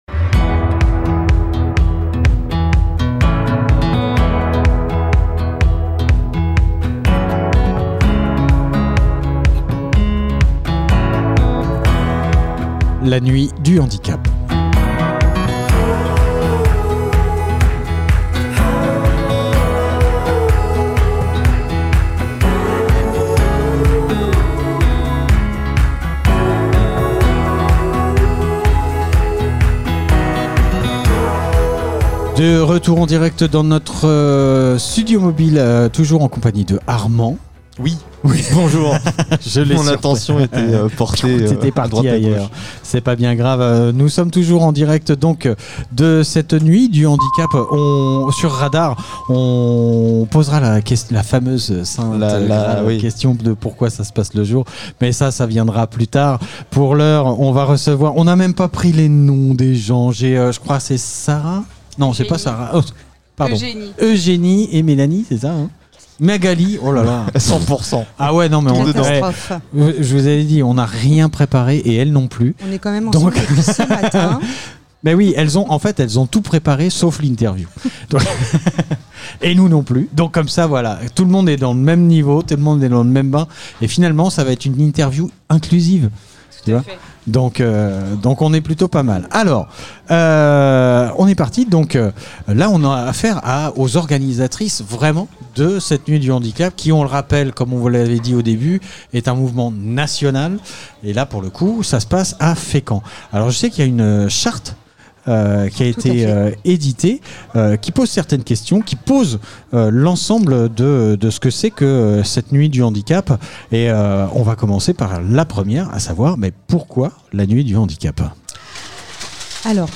La nuit du handicap événement Interview handicap fécamp ville de fécamp nuit ville Nuit du Handicap